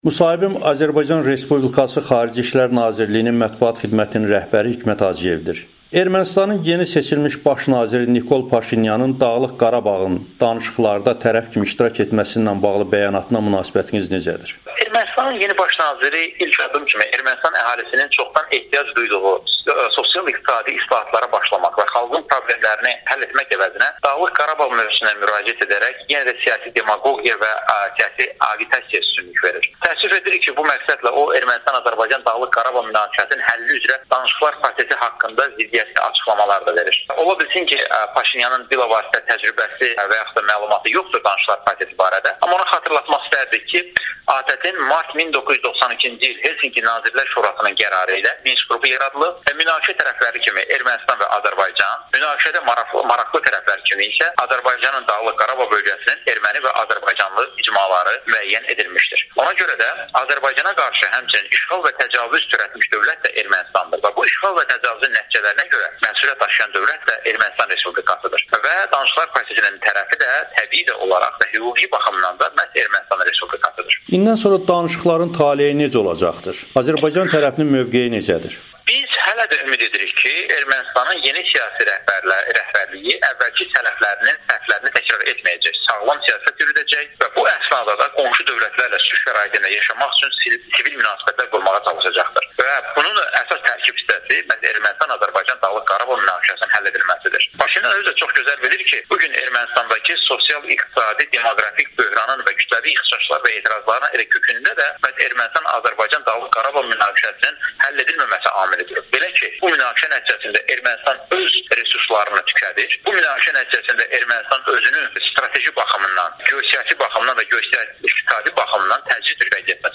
Hikmət Hacıyev: Azərbaycan Paşinyanın şərt irəli sürməsini qəbul etmir [Audio-müsahibə]